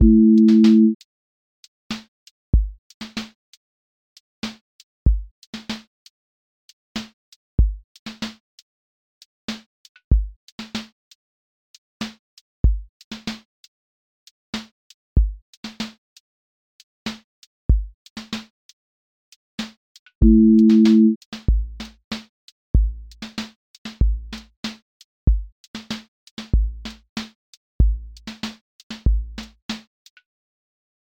QA Listening Test r&b Template: rnb_pocket
r&b pocket with warm chord bed
• voice_kick_808
• voice_snare_boom_bap
• voice_hat_rimshot
• voice_sub_pulse